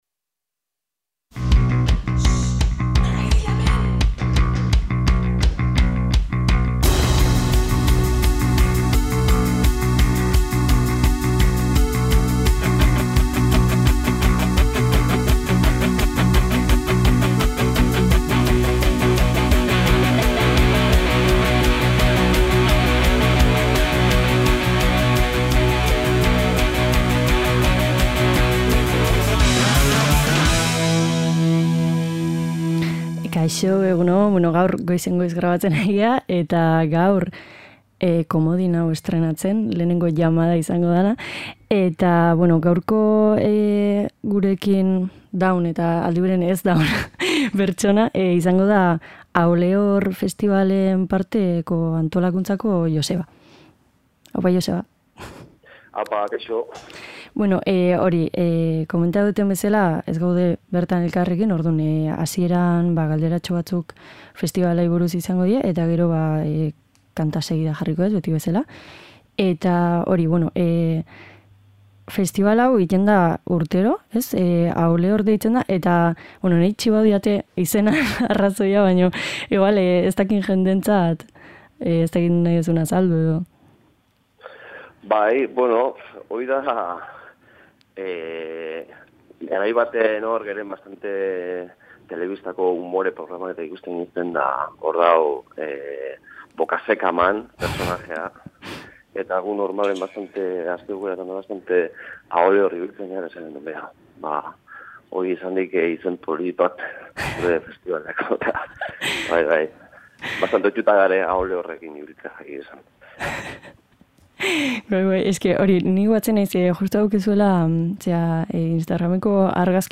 Ondoren, beti bezala, kanta zerrenda. Jaialditik pasatako taldeen kanta azkarrak batu ditugu, aurten bertaratuko direnekin batera.